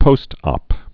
(pōstŏp) Informal